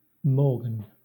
Ääntäminen
Ääntäminen Southern England US : IPA : /ˈmɔɹɡən/ Haettu sana löytyi näillä lähdekielillä: englanti Kieli Käännökset ranska Morgane , morgan ruotsi Morgan Määritelmät Erisnimi (countable) A unisex given name .